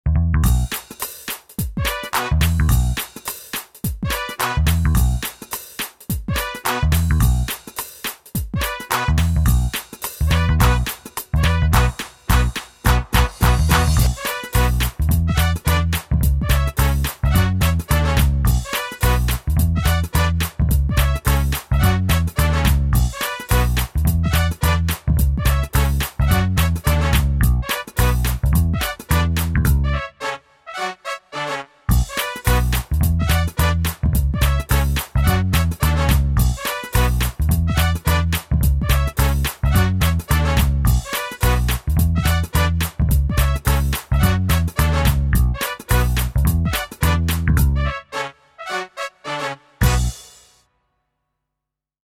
instrumental music cues can also be used to